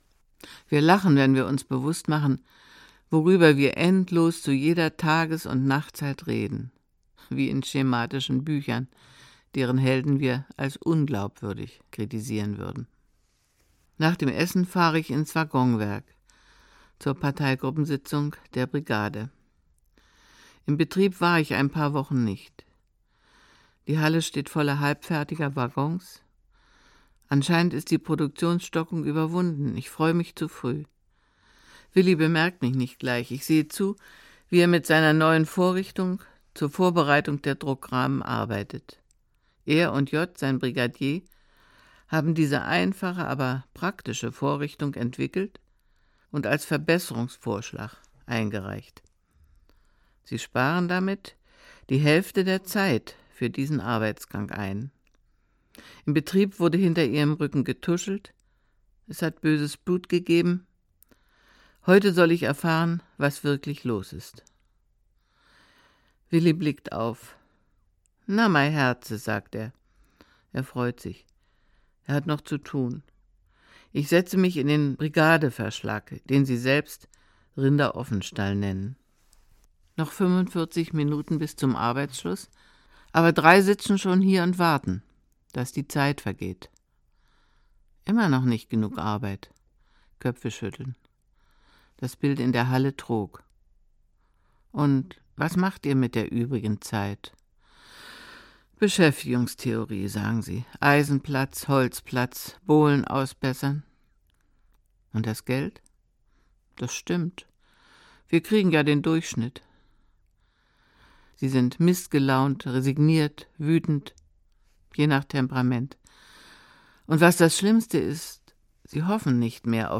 Ein Tag im Jahr - Christa Wolf - Hörbuch